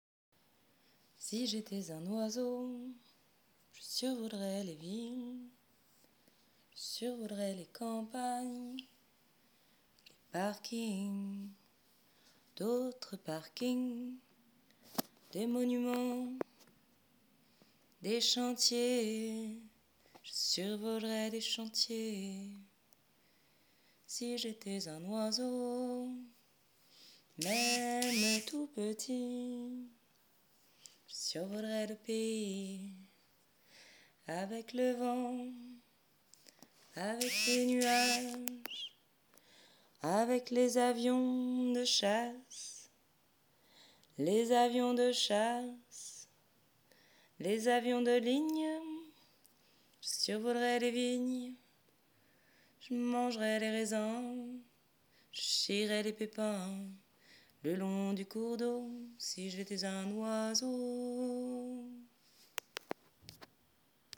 parlé chanté